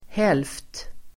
Ladda ner uttalet
Uttal: [hel:ft]